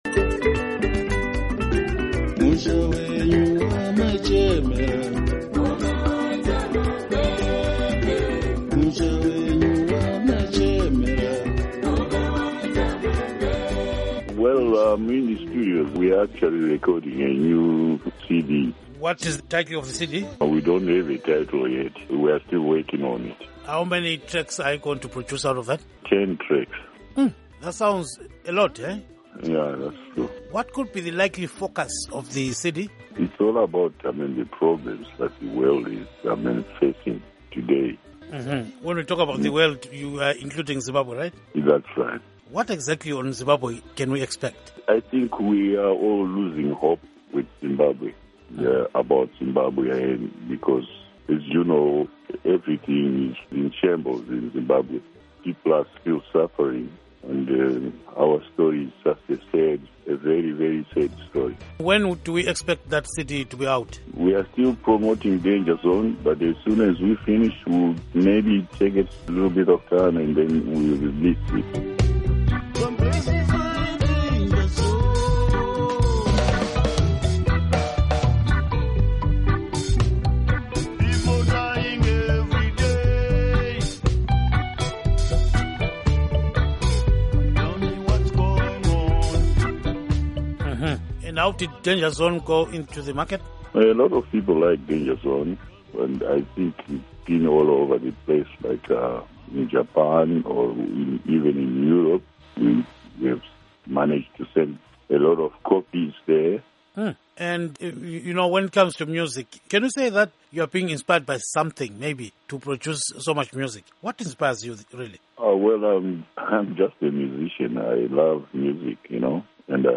Interview With Musician Thomas Mapfumo